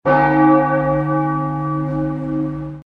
알림음 8_교회종소리.ogg